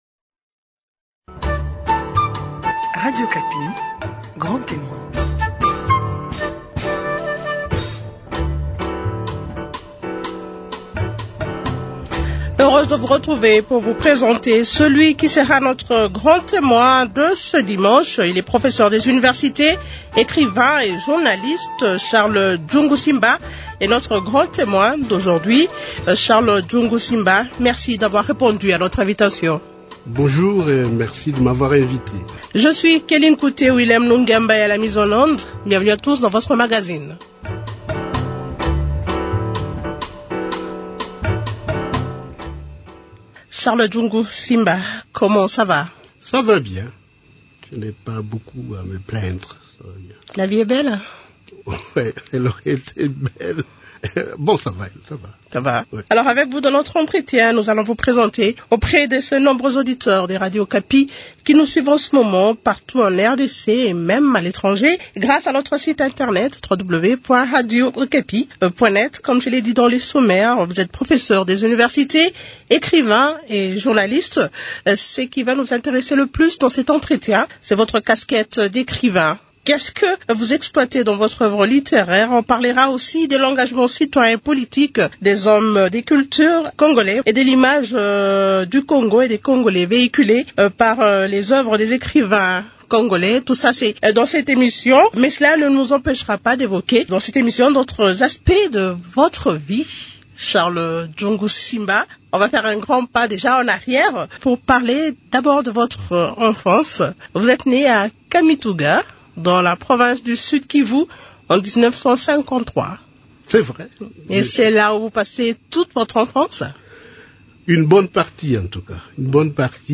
Dans son entretien